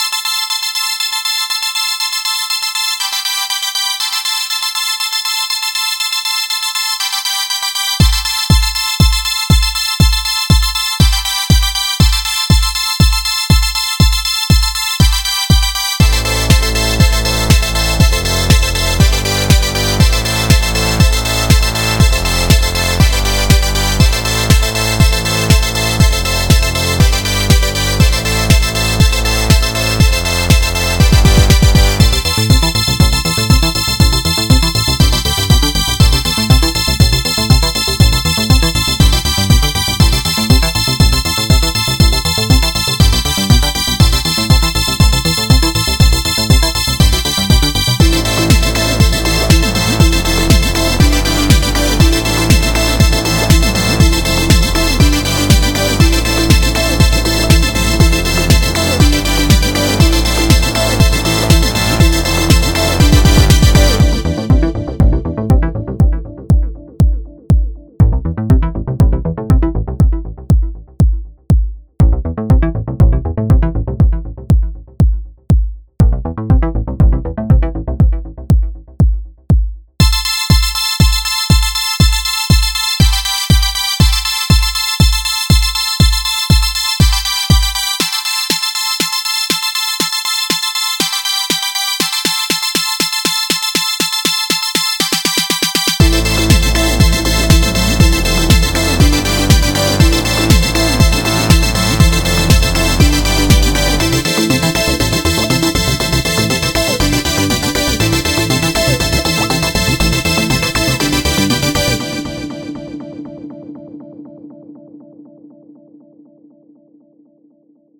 jajajaj no me pudiste reclamar en nada. y pues si, no es mentira, un día es un día no? además estaba intentando mejorar ciertas cocitas con la EQ.
electro.mp3